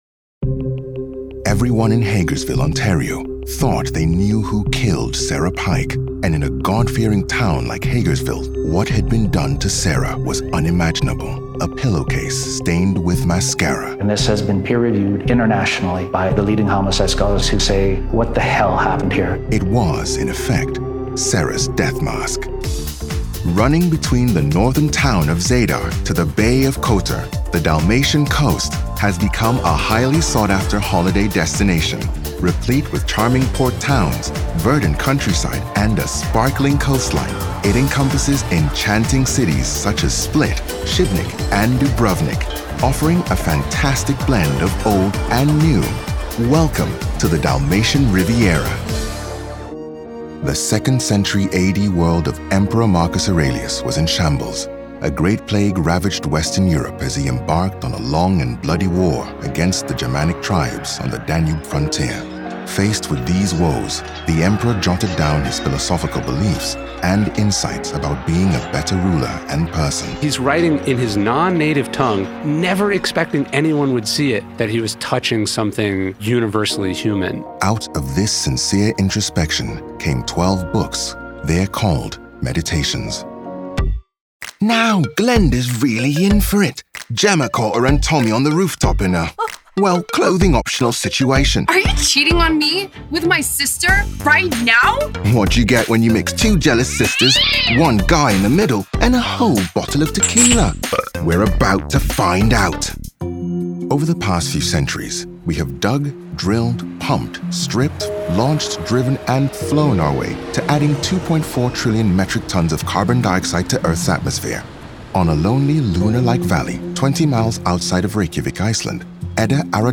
English (International)
Deep, Natural, Distinctive, Playful, Versatile
Audio guide